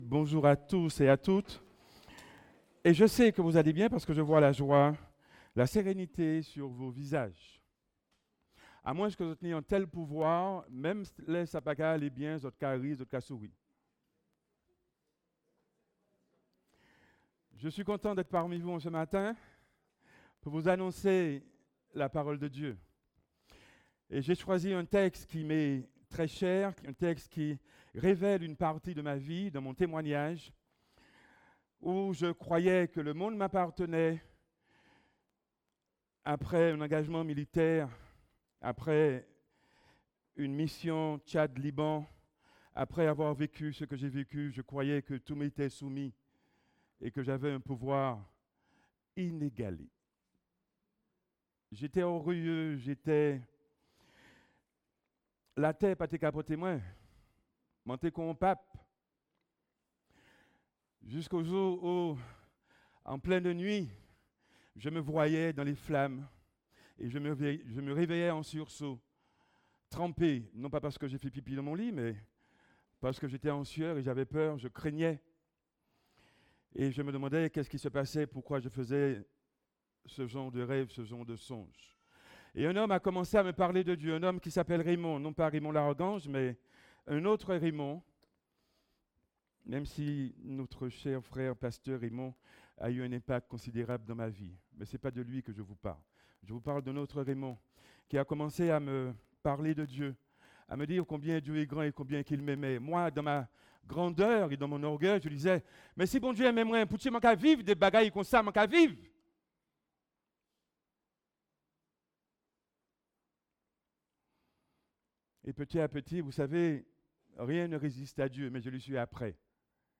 De l’échec à la vie Prédicateur